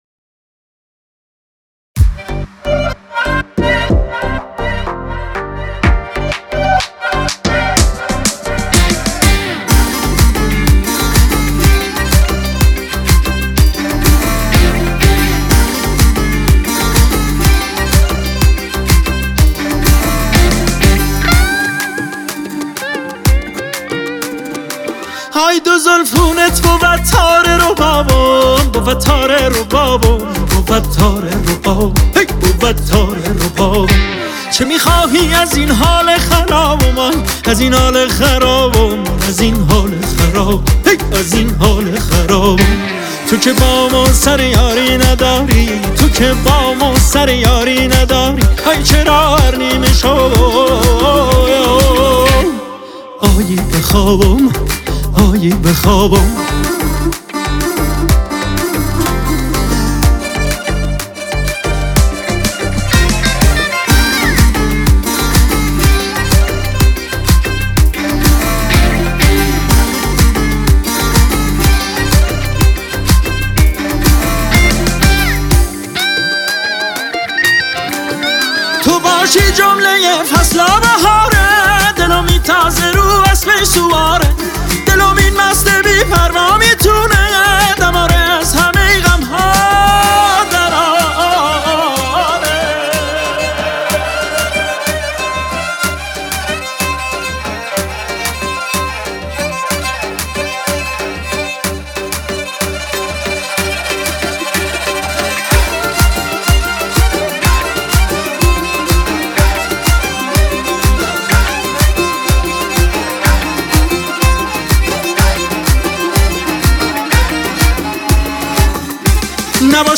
شاد و خیلی خوب و سنتی